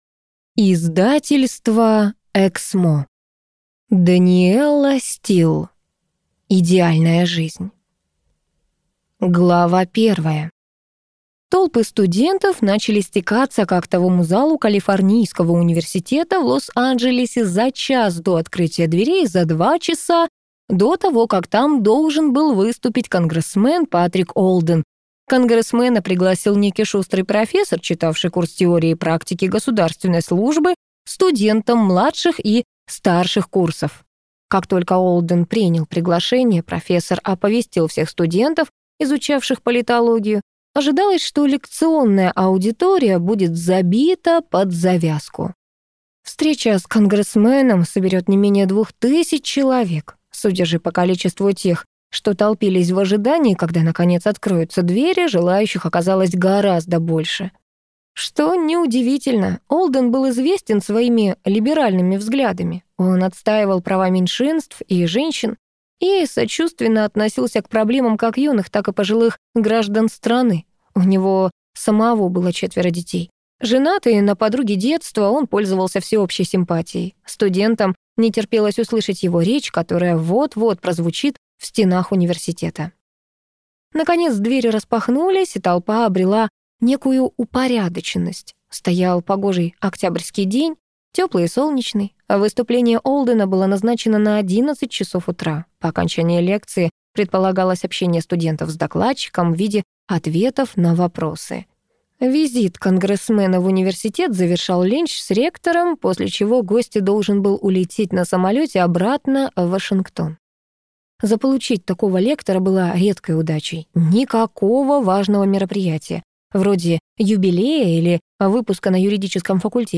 Аудиокнига Идеальная жизнь | Библиотека аудиокниг